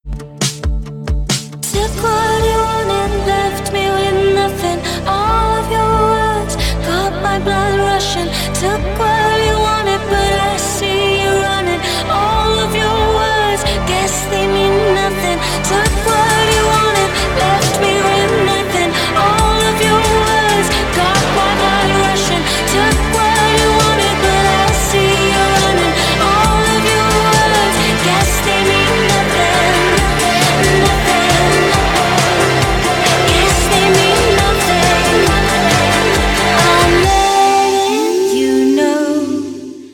dance
club